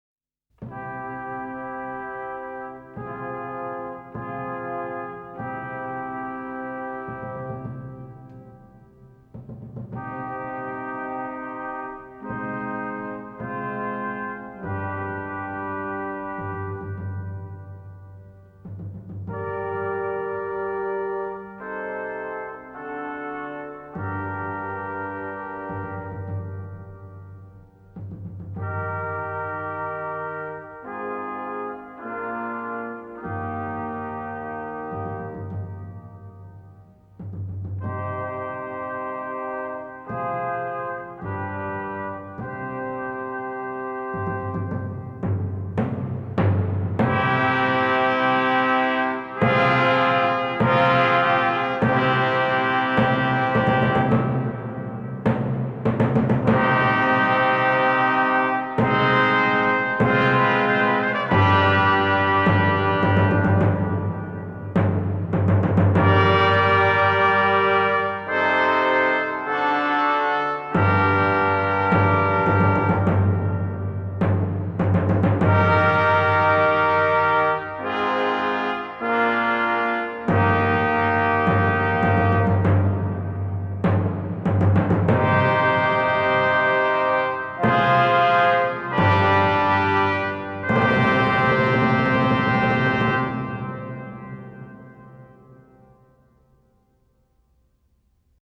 It is also profoundly moving music.